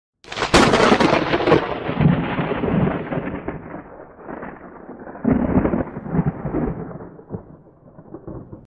Efecto-de-truenos-192-kbps-.mp3
KDfcsJiZz08_Efecto-de-truenos-192-kbps-.mp3